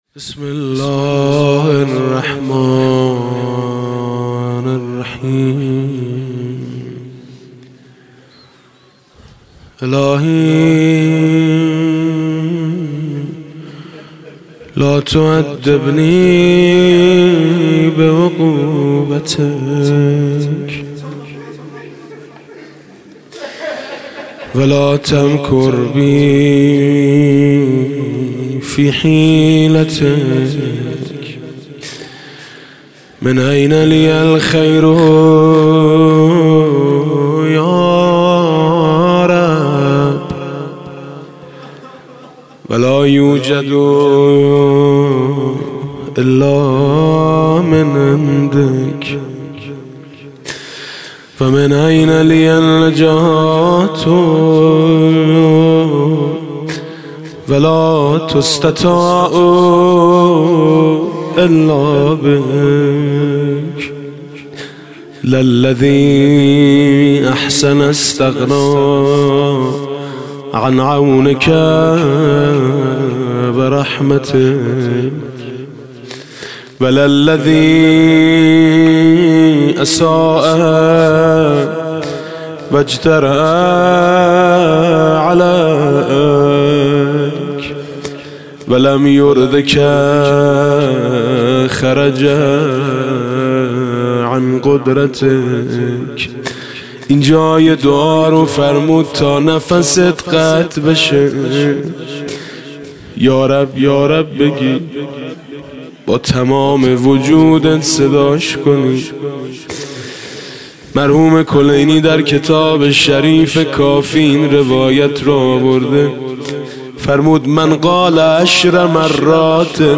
28 اردیبهشت 97 - هیئت میثاق با شهدا - دعای ابوحمزه ثمالی - بخش اول